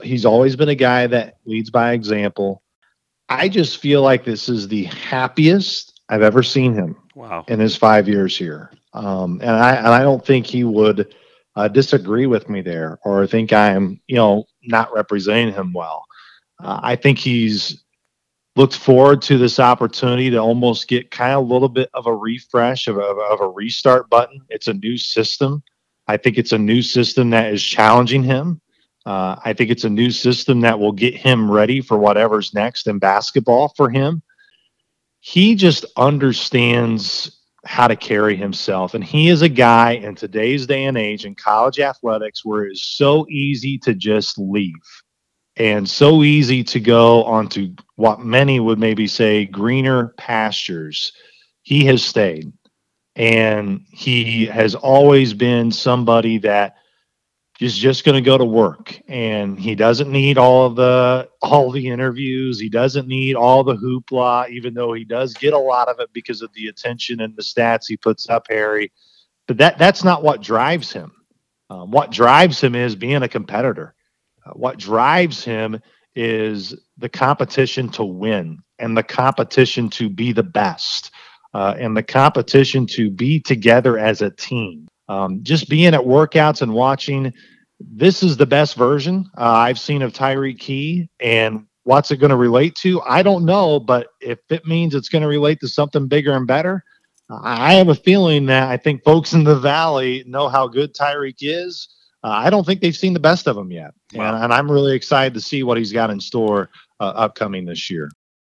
During our interview we discussed both the roster and challenges that lie ahead.